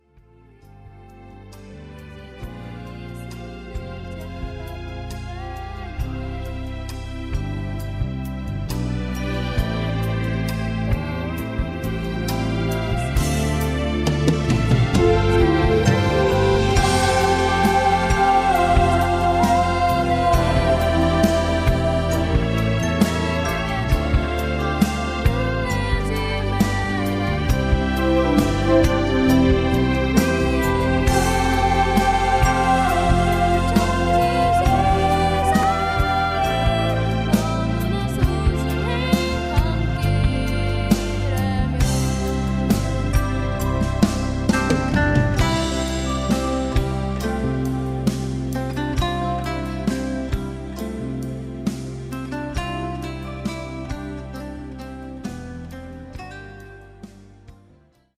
음정 -1키 4:18
장르 가요 구분 Voice MR
보이스 MR은 가이드 보컬이 포함되어 있어 유용합니다.